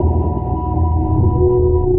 sonarPingSuitCloseShuttle1.ogg